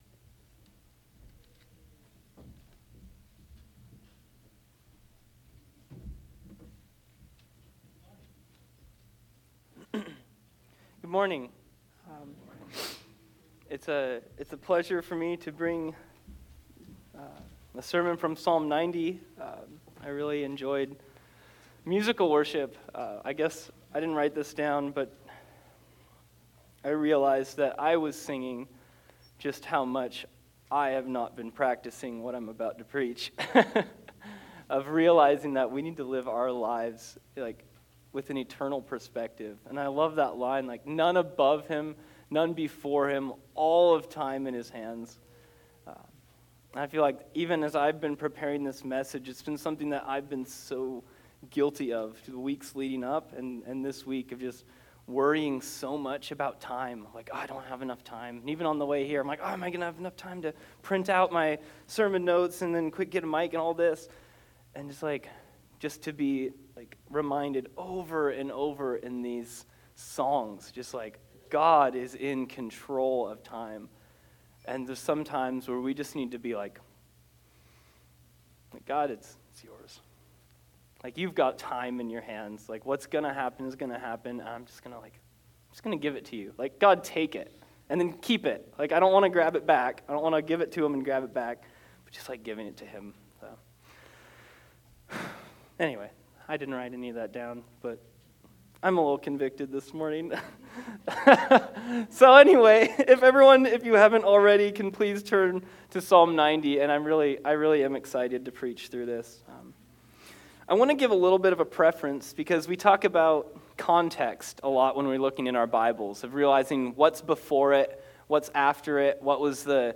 Passage: Psalm 90 Service Type: Sunday Service